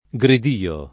gridio [ g rid & o ] s. m.